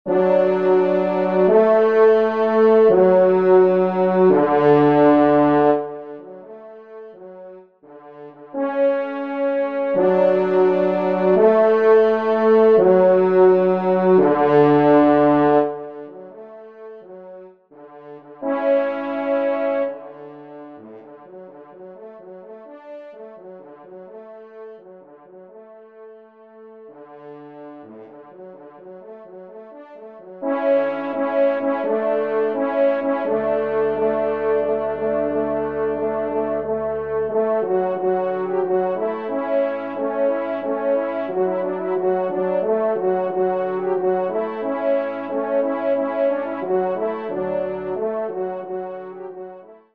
Genre :  Divertissement pour Trompes ou Cors en Ré
3e Trompe